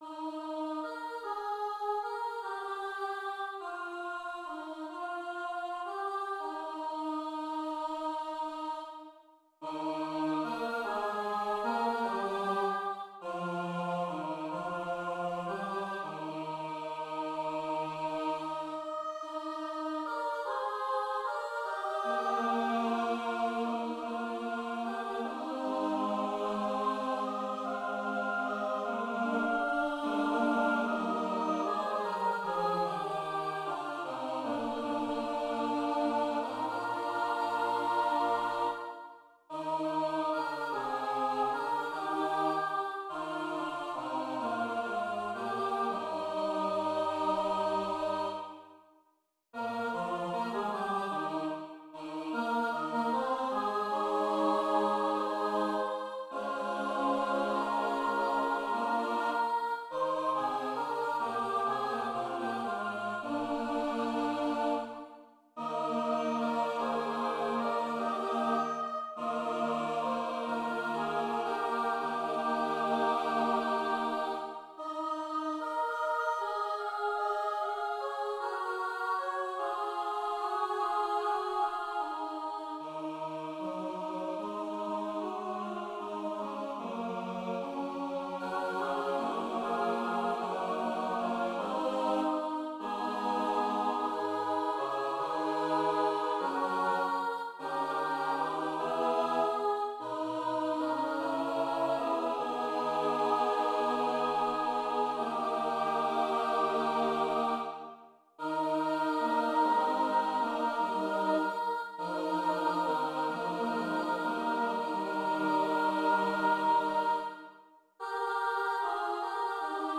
Voicing/Instrumentation: SATB
Choir with Congregation together in certain spots